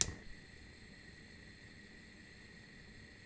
Gas Hob 01.wav